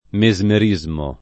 [ me @ mer &@ mo ]